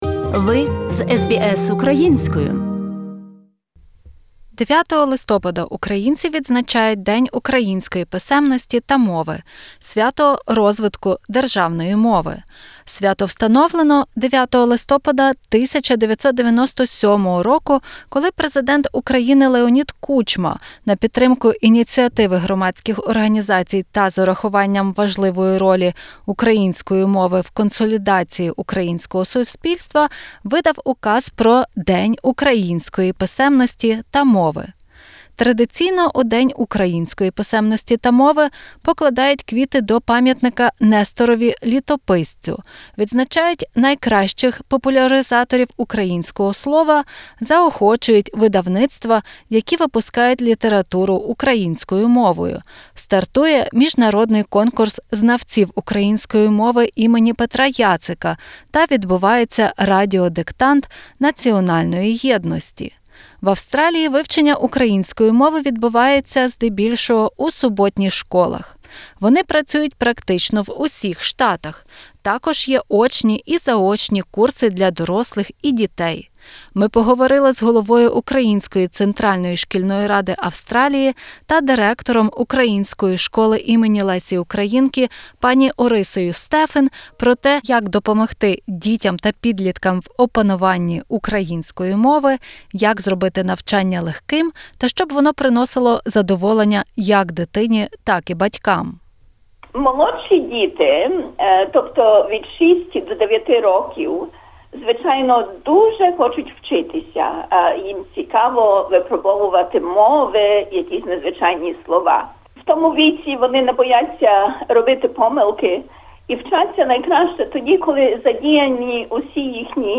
Розмова